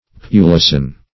pulasan - definition of pulasan - synonyms, pronunciation, spelling from Free Dictionary